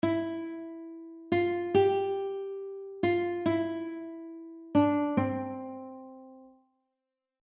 You will occasionally encounter staccato and dotted notes.
Grade 2 Guitar Sight Reading Exercise